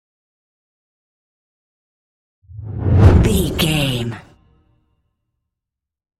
Whoosh deep fast
Sound Effects
Atonal
Fast
dark
intense
tension
whoosh
sci fi